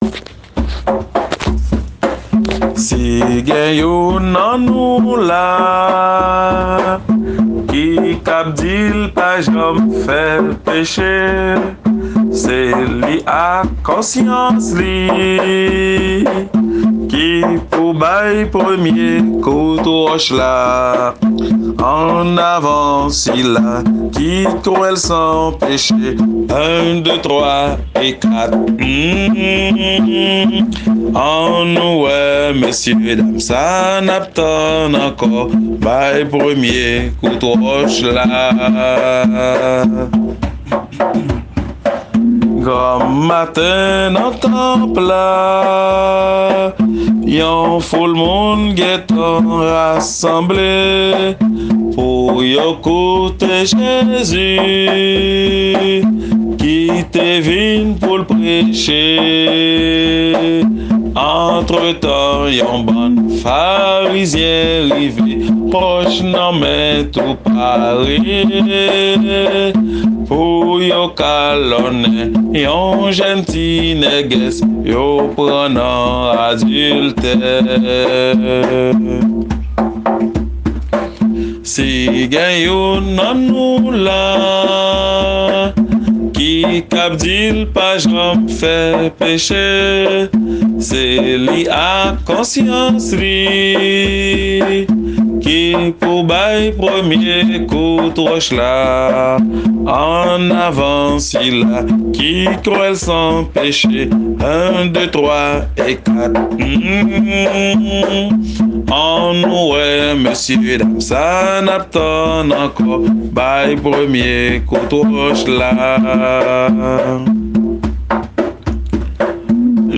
Kantik Kréyòl